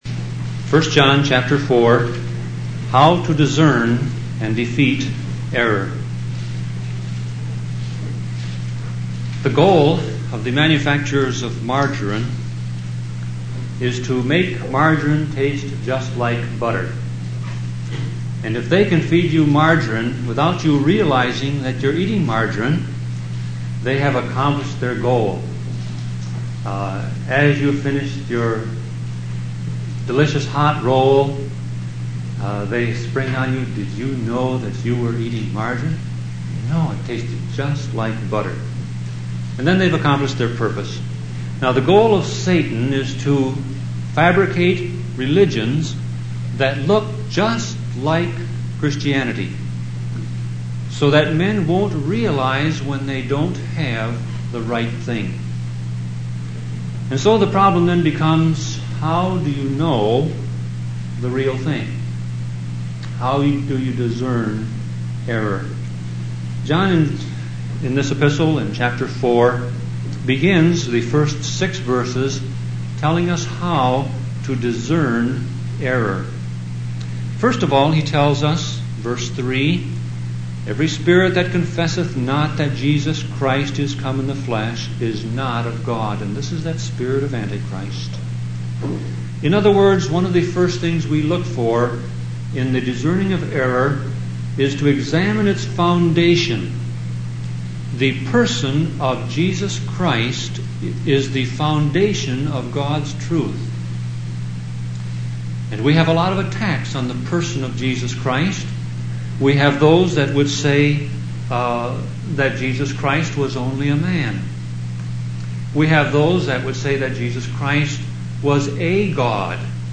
Sermon Audio Passage